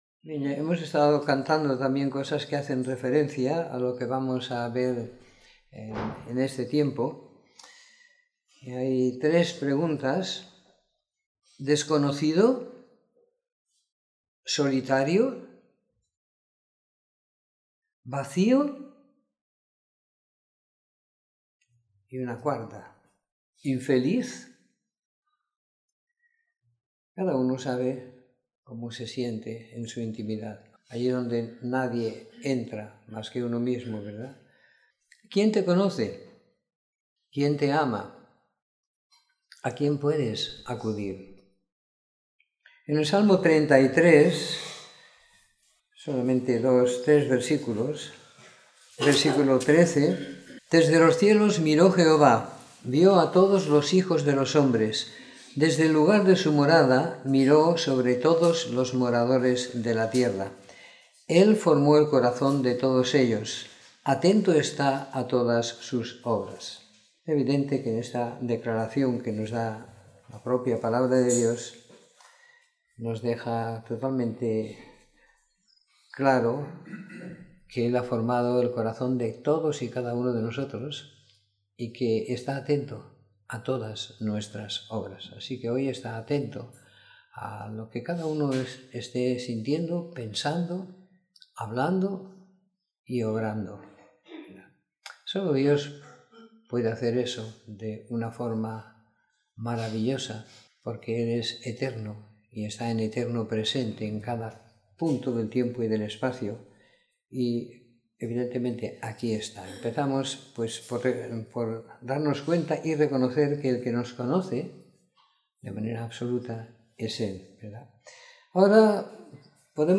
Domingo por la Mañana . 03 de Diciembre de 2017